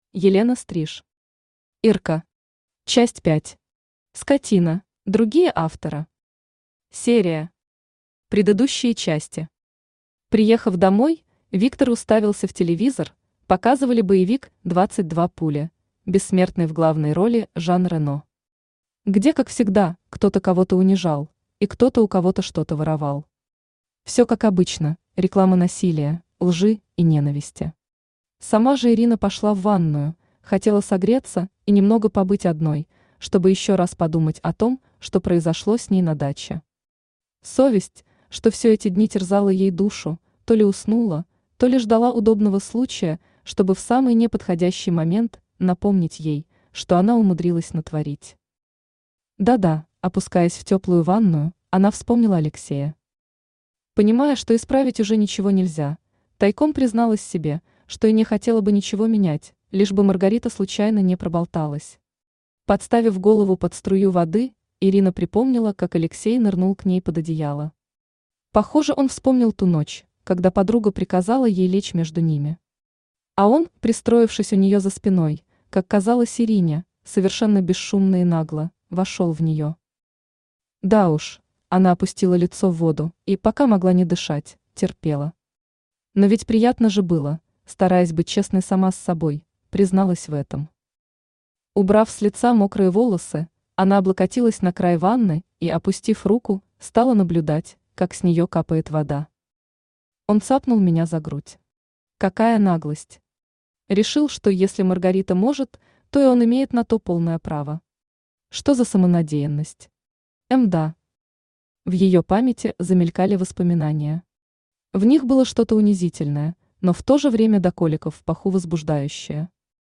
Аудиокнига Ирка. Часть 5. Скотина | Библиотека аудиокниг
Скотина Автор Елена Стриж Читает аудиокнигу Авточтец ЛитРес.